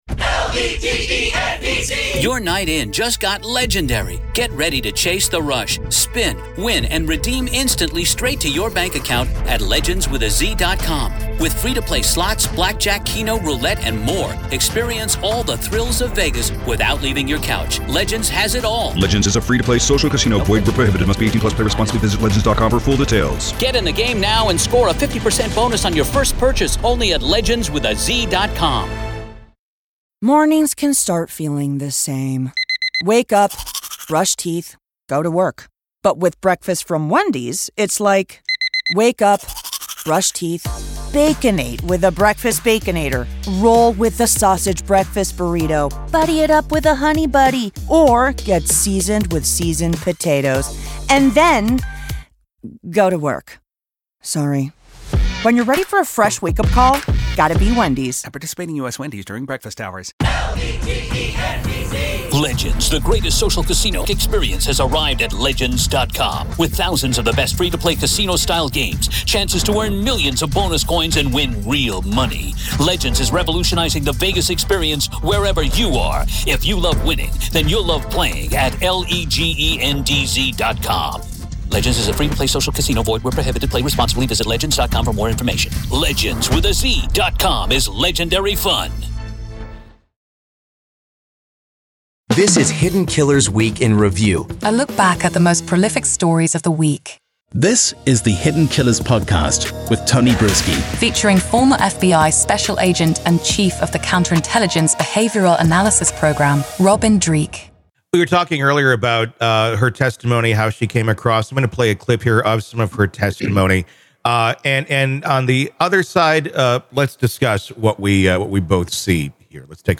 Welcome to the "Week in Review," where we delve into the true stories behind this week's headlines.
Each episode navigates through multiple stories, illuminating their details with factual reporting, expert commentary, and engaging conversation.
Expect thoughtful analysis, informed opinions, and thought-provoking discussions beyond the 24-hour news cycle.